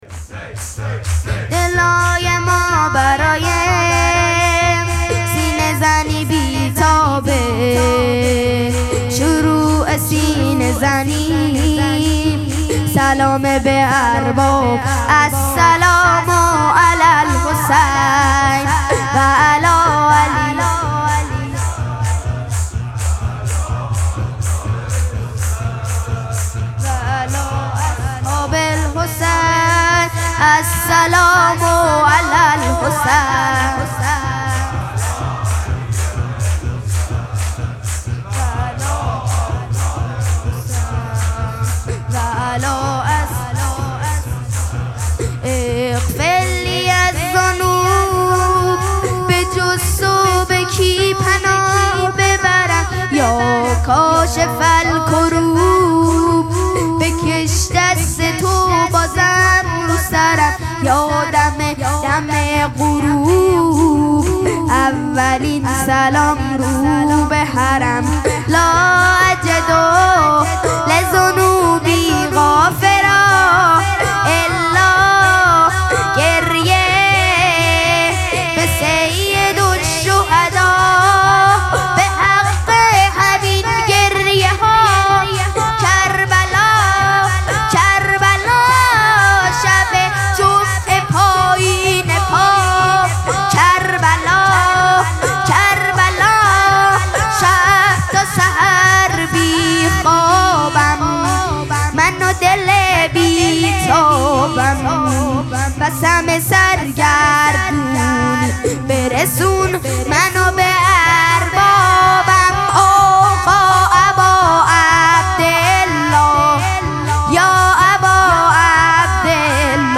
مراسم مناجات شب هجدهم ماه مبارک رمضان
حسینیه ریحانه الحسین سلام الله علیها
شور